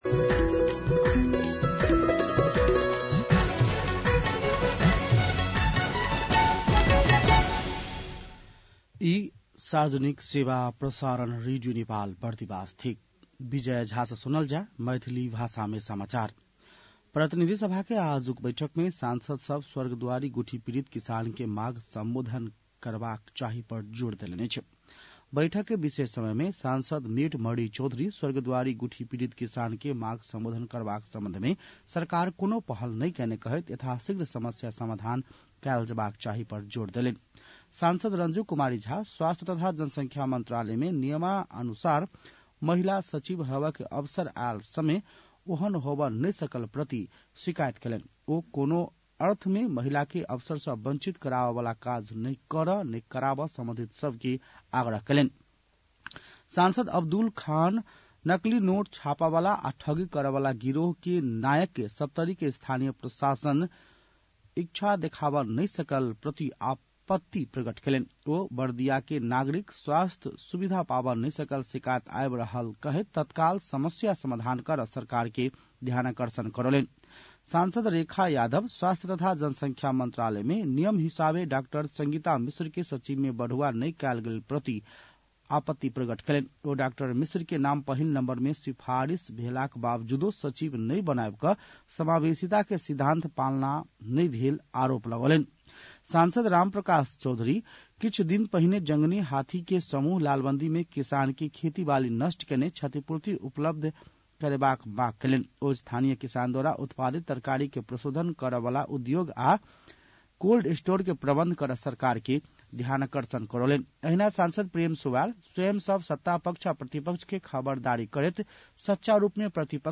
मैथिली भाषामा समाचार : २३ फागुन , २०८१